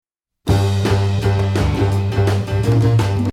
The song is built around a simple riff.